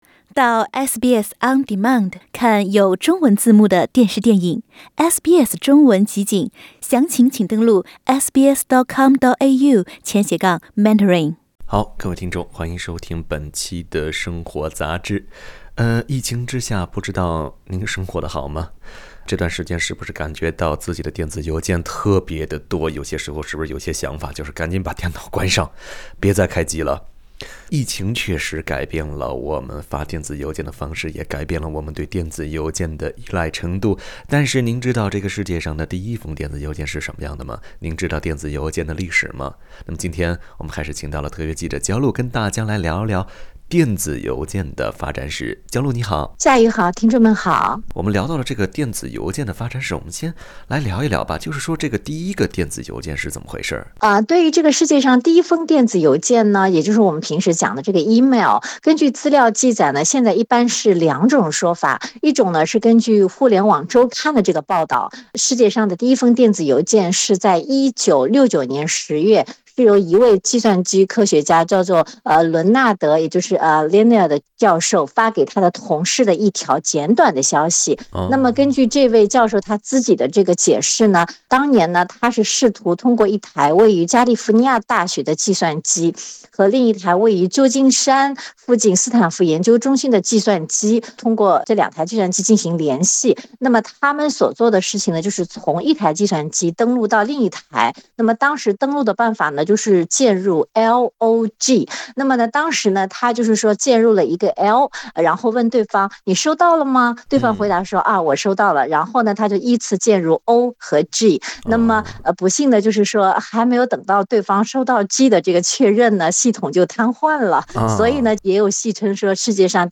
您知道世界上第一封电邮的历史吗？疫情又是如何改变我们对电邮的认知？（点击图片音频，收听采访）